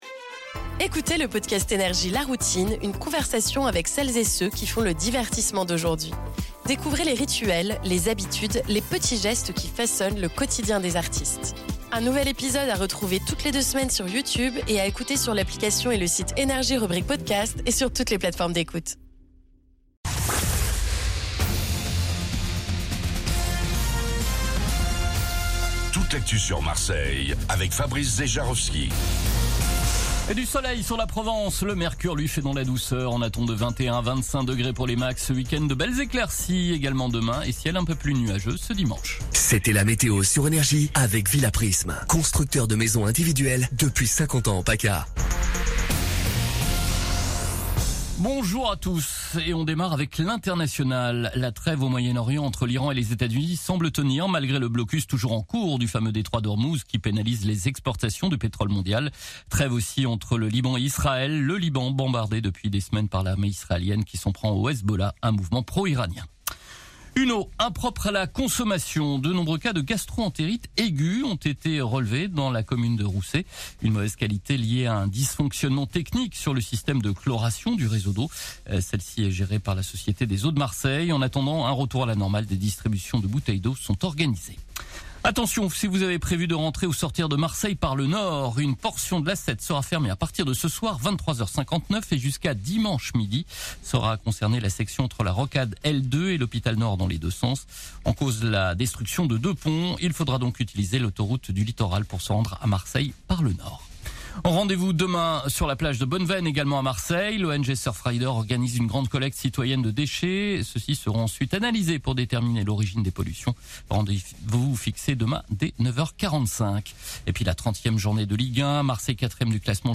Réécoutez vos INFOS, METEO et TRAFIC de NRJ MARSEILLE du vendredi 17 avril 2026 à 06h00